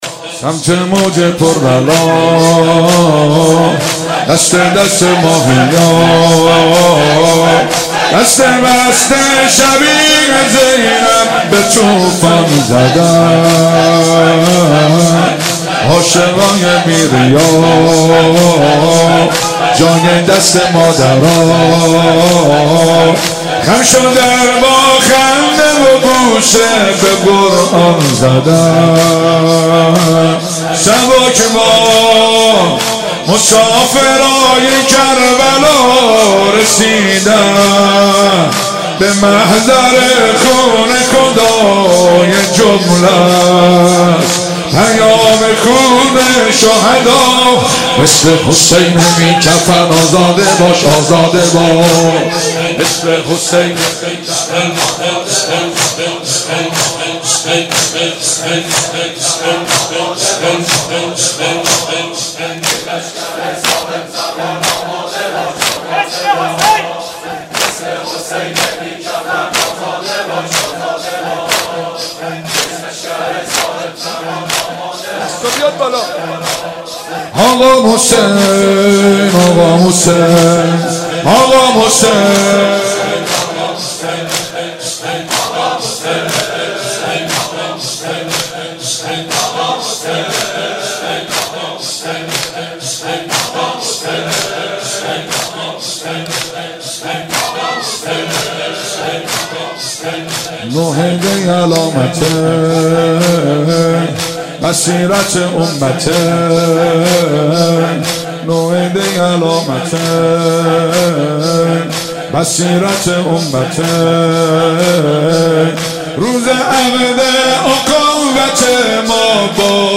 مداحی بسیار زیبا